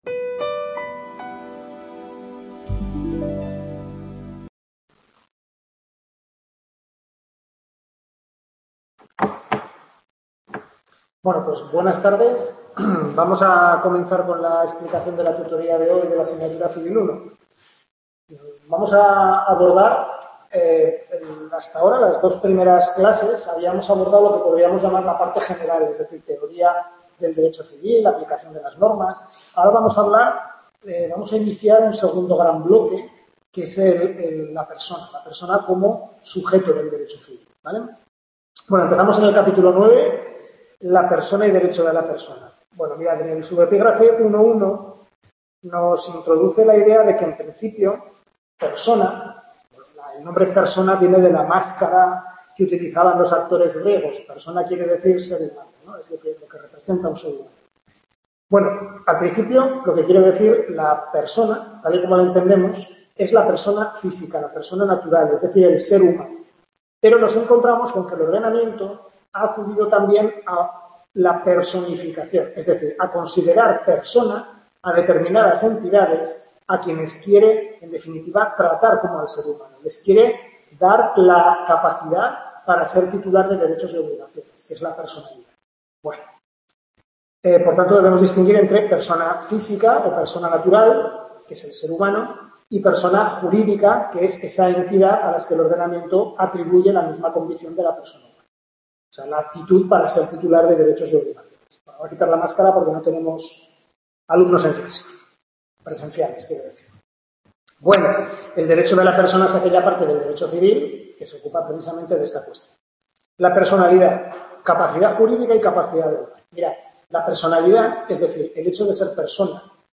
Tutoría Civil I capítulos 9-12 del Manual Prof. Lasarte